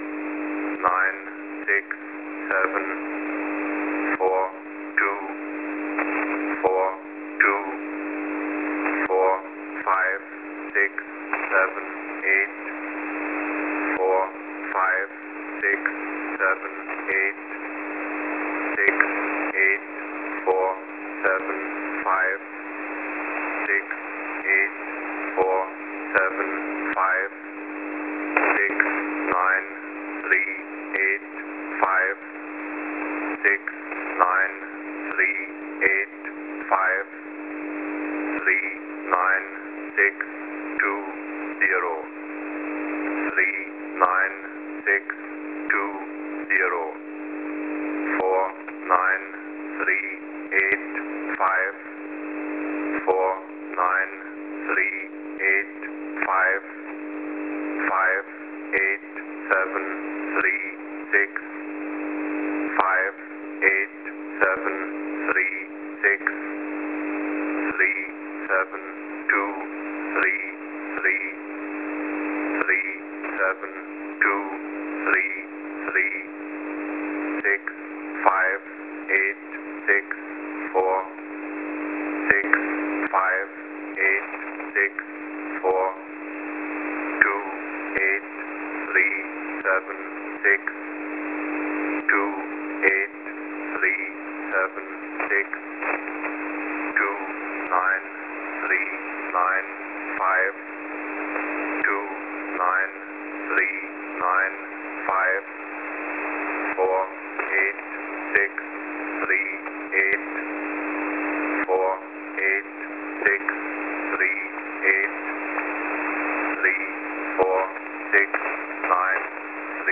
Mode: USB + Carrier Frequency: 5197.00kHz